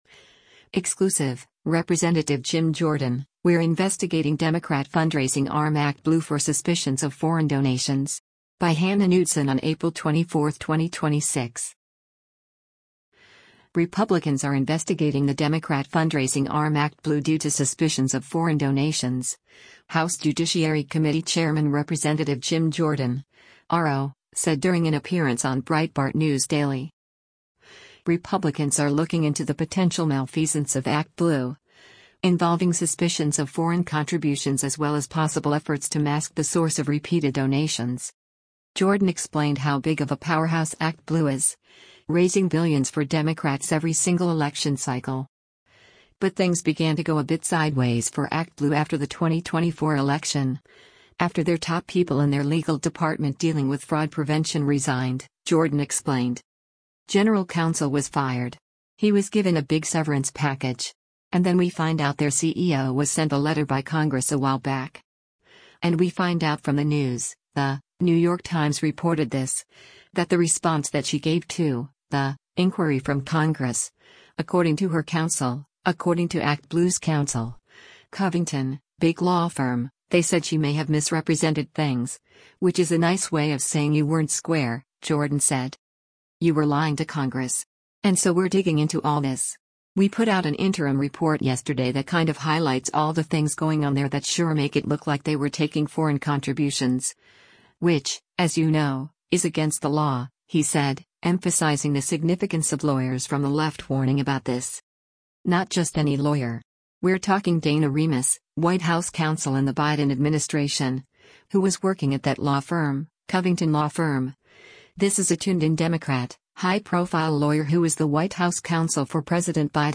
Republicans are investigating the Democrat fundraising arm ActBlue due to suspicions of foreign donations, House Judiciary Committee Chairman Rep. Jim Jordan (R-OH) said during an appearance on Breitbart News Daily.
Breitbart News Daily airs on SiriusXM Patriot 125 from 6:00 a.m. to 9:00 a.m. Eastern.